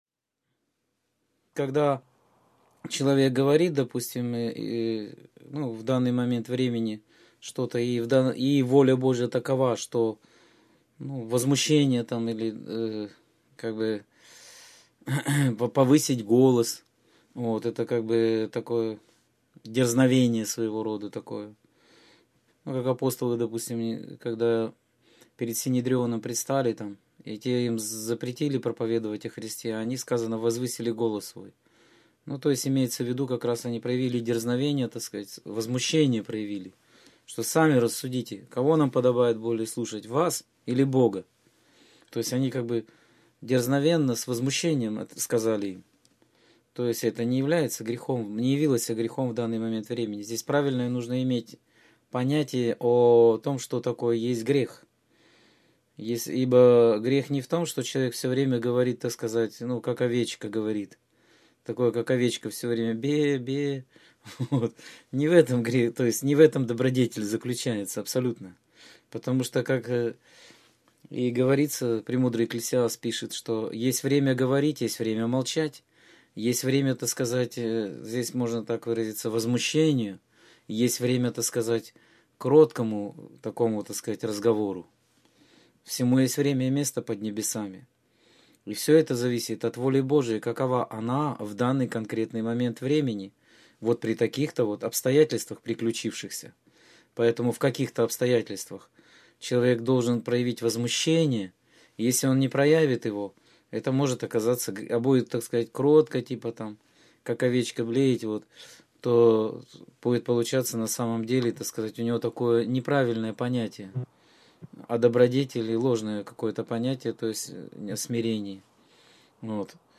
Скайп-беседа 28.12.2013